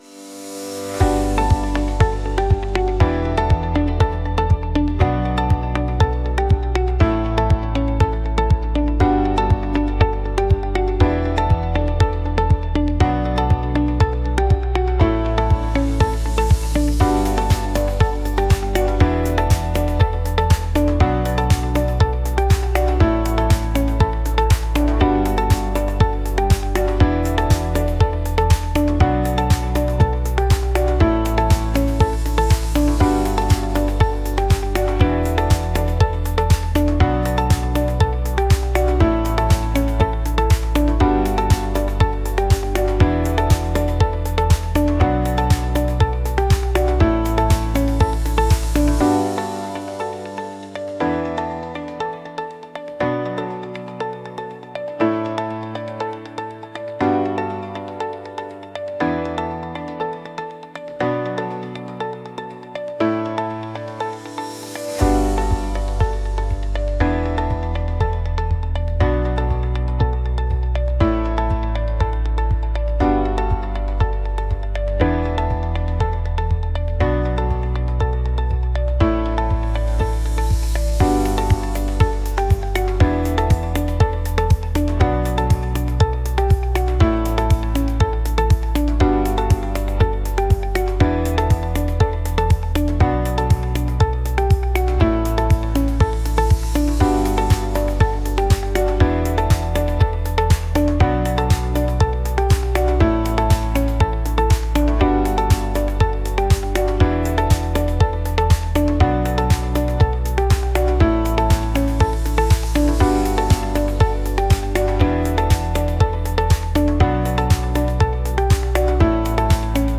electronic | upbeat | pop